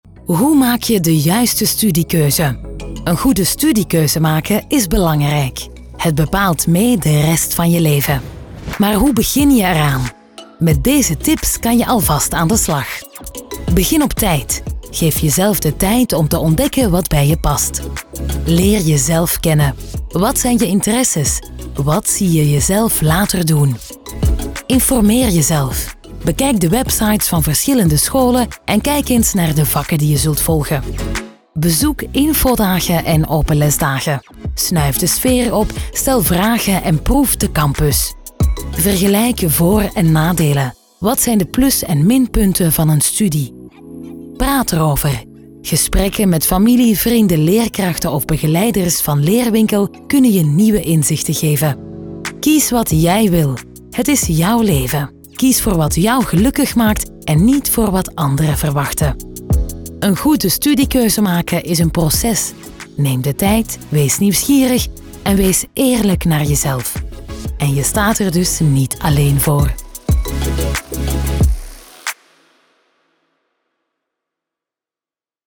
Diep, Jong, Speels, Veelzijdig, Warm
E-learning